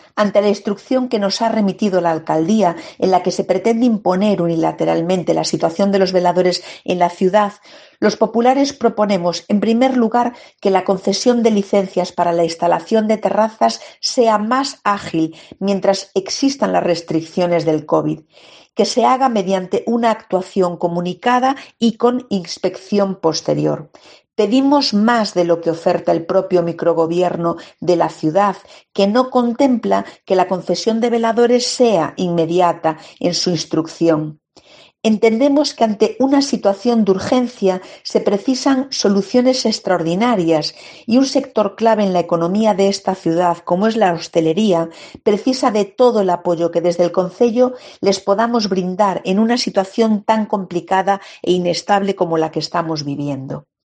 La portavoz del PP, Flora Moure, explica la petición de licencias exprés para veladores